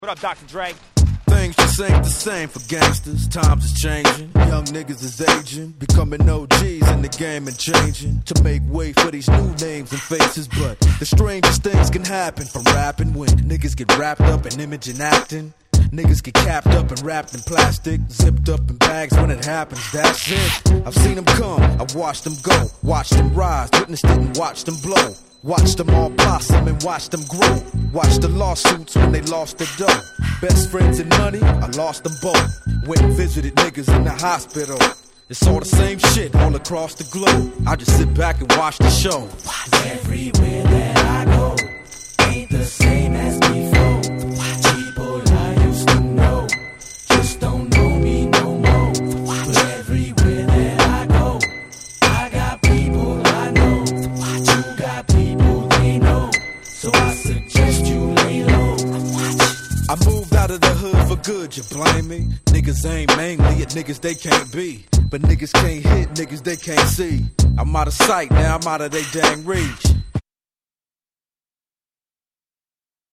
G-Rap Gangsta Rap